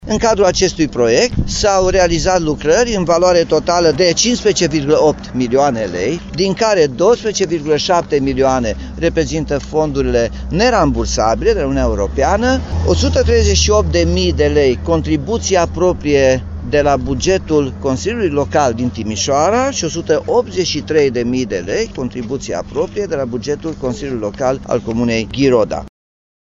O mare parte din banii necesari executării proiectului, a venit din partea Fondului European pentru Dezvoltare Regională, spune primarul Timişoarei, Nicolae Robu.